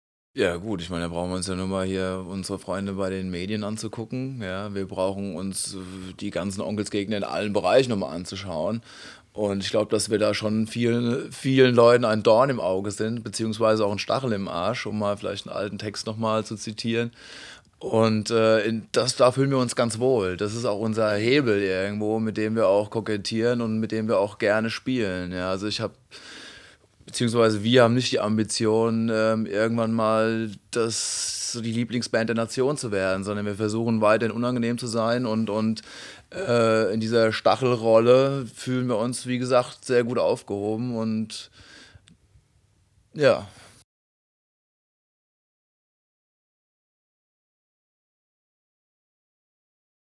Anbei hört hier ein paar Worte der Band zum Release des Albums, direkt aus dem Presse-Kit zur Veröffentlichung.